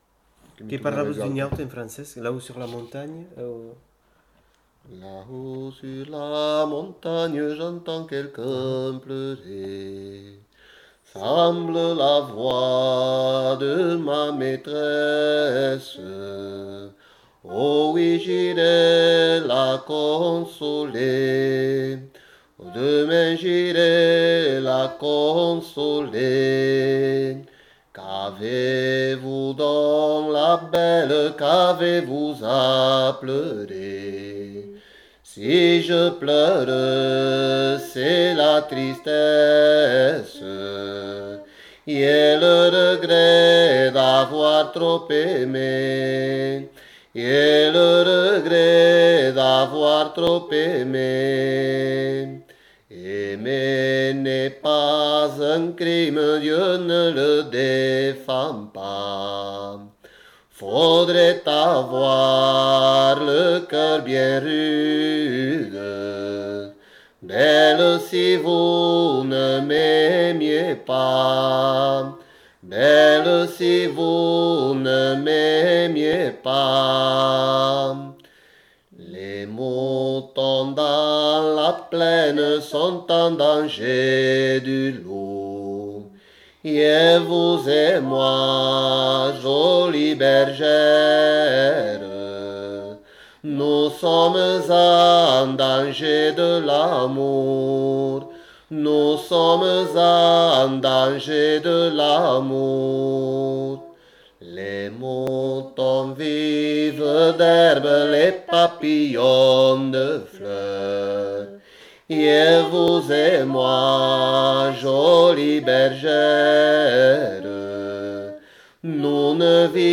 Aire culturelle : Bigorre
Lieu : Ayzac-Ost
Genre : chant
Effectif : 2
Type de voix : voix d'homme ; voix de femme
Production du son : chanté